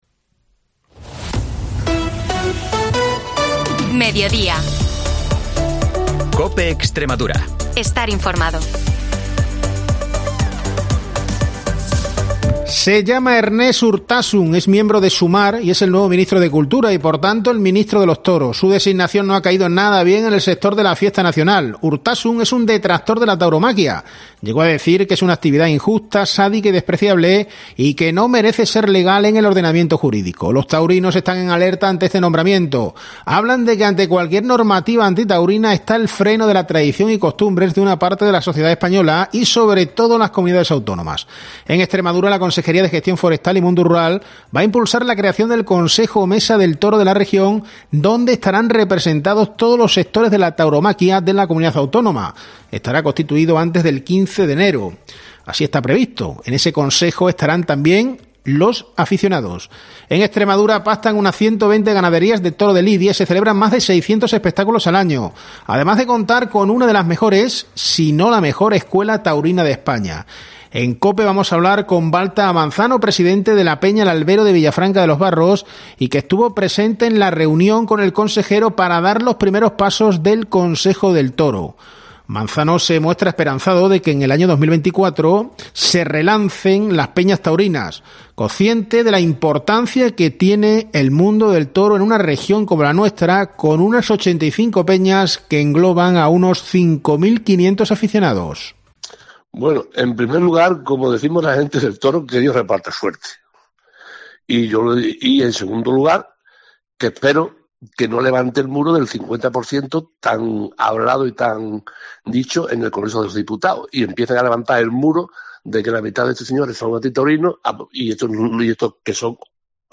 Información y entrevistas